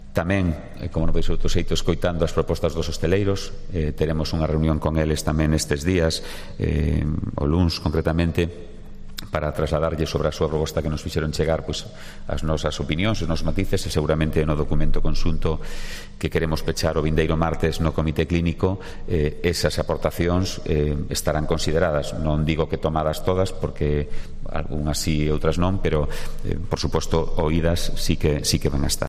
García Comesaña habla de la reunión con los hosteleros la procima semana